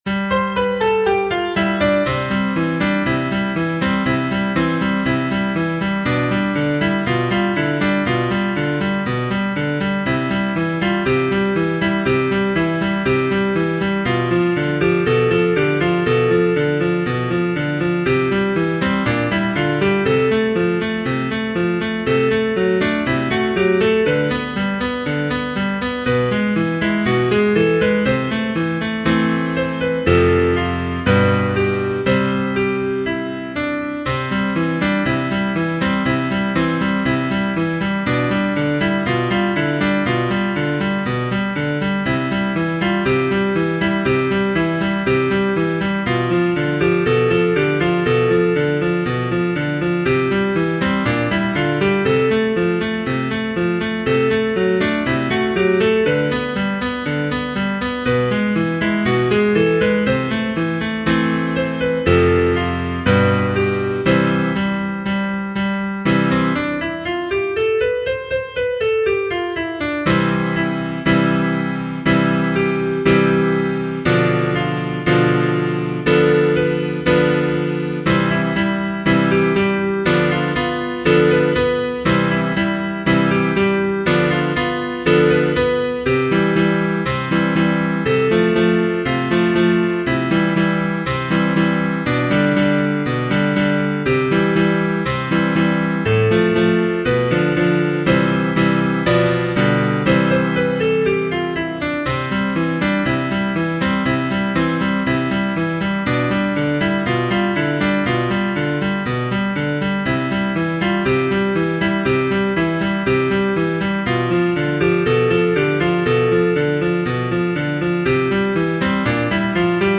ハンドベル